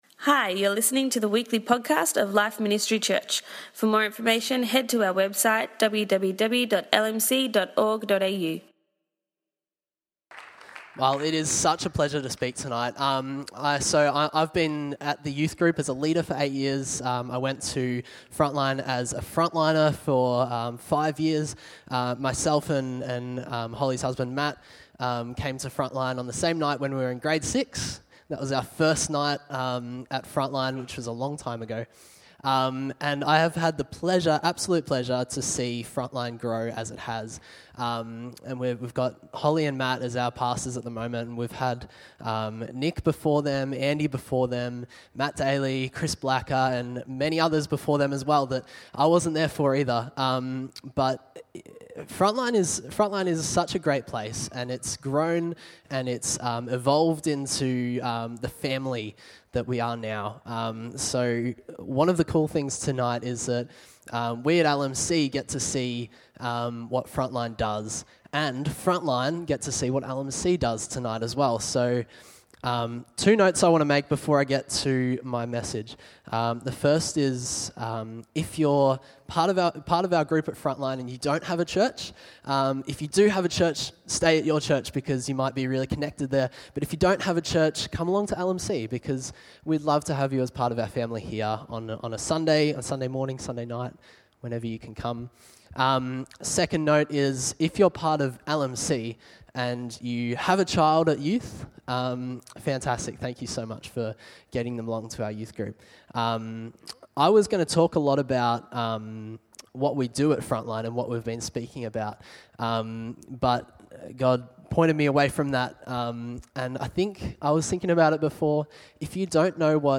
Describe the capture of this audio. For tonight's Youth Service